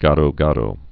(gädōdō)